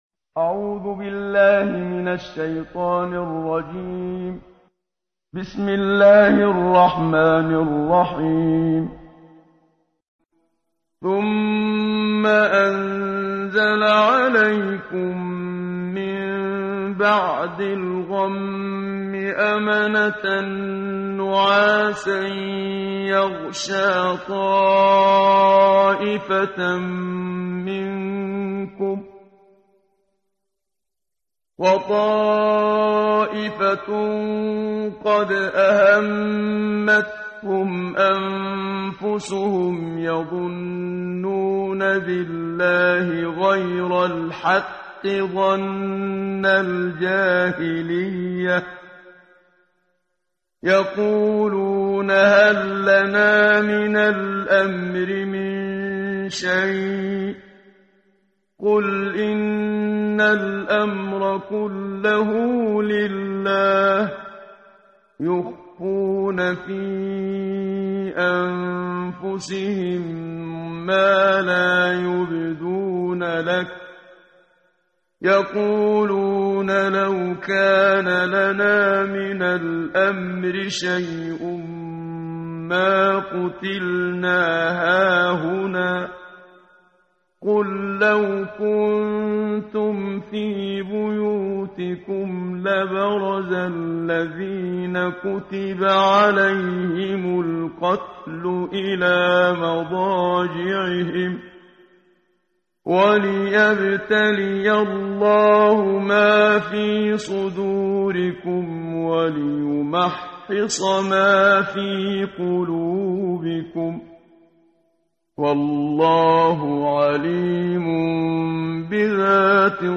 قرائت قرآن کریم ، صفحه 70، سوره مبارکه آلِ عِمرَان آیه 154 تا 157 با صدای استاد صدیق منشاوی.